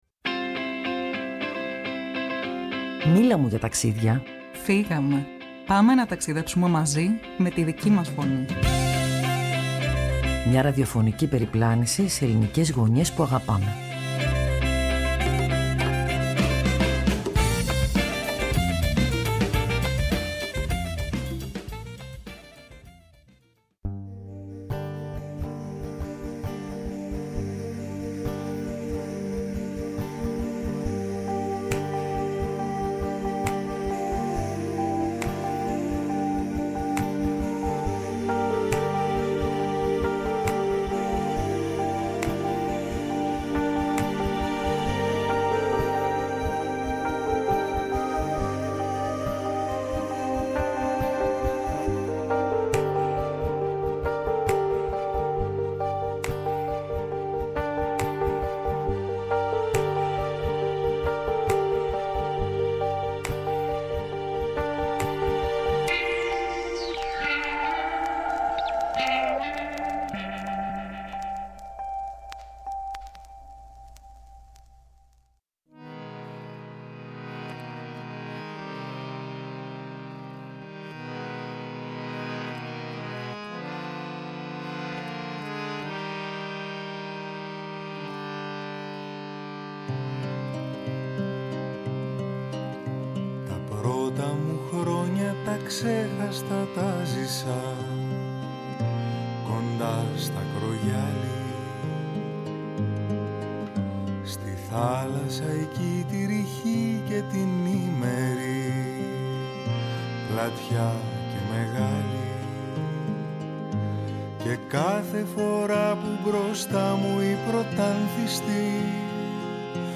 Μέσα από λόγια, μνήμες και μουσικές, η εκπομπή έδειξε πως το Μεσολόγγι δεν ανήκει μόνο στην Ιστορία, αλλά και στο Μέλλον.